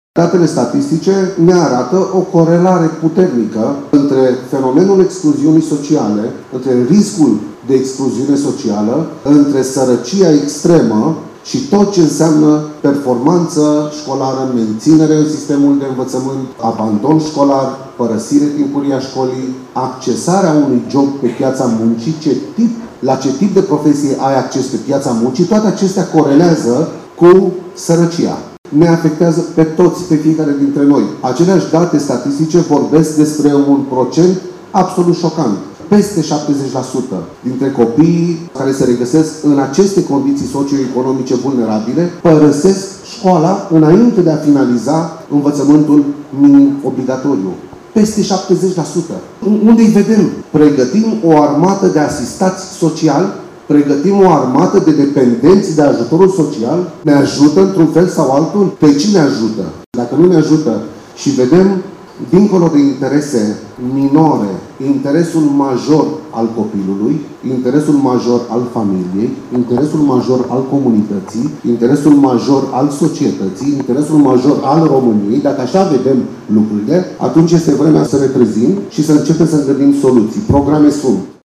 Abandonul școlar – o criză extrem de gravă cu care se confruntă România, a fost tema dezbaterii organizată ieri, la Botoșani, de Confederația Națională pentru Antreprenoriat Feminin (CONAF), în cadrul proiectului național „Pactul pentru Tineri”, în parteneriat cu Ministerul Educației, Ministerul Familiei, Tineretului și Egalității de Șanse, Ministerul Muncii și Solidarității Sociale, autoritățile locale, Banca Mondială și UNICEF.
Marian-Daragiu-subsecretar-de-Stat-Ministerul-Educatiei.mp3